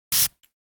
perfume3.ogg